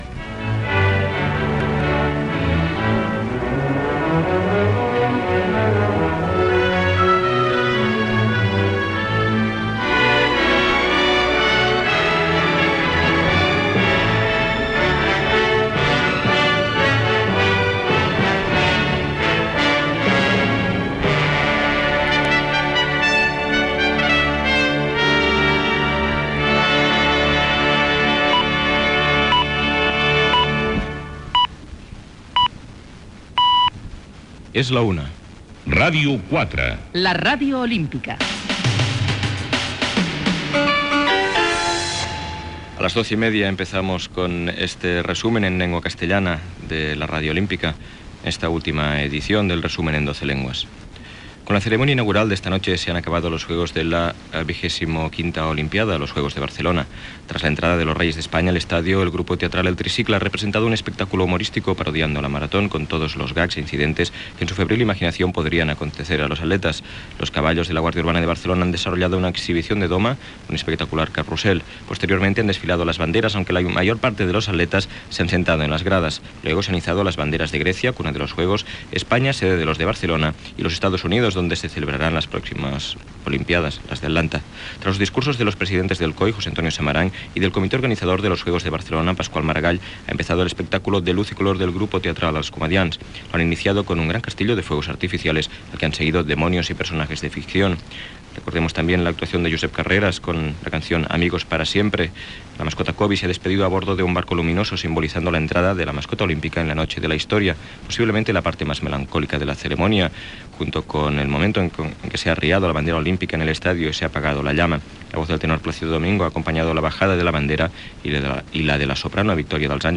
aeb5ca9007edf3715c17f3243c4d0cedc3b11c6f.mp3 Títol Ràdio 4 la Ràdio Olímpica Emissora Ràdio 4 la Ràdio Olímpica Cadena RNE Titularitat Pública estatal Descripció Sintonia, identificació, hora, informatius en diversos idiomes. Informació de la cerimònia de cloenda dels Jocs Olímpics de Barcelona 1992 i balanç dels resultats en castellà, italià, japonès, francès, portuguès i rus.